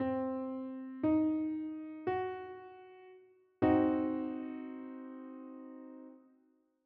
The diminished triad can be compared to the minor triad, as again it only has one note different, again the 5th (top note), but in this case it gets lowered by one semitone.
Figure 11.4 C diminished triad displayed melodically.
C-Diminished-Triad-S1.wav